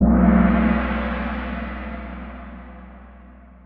kahoot-gong.wav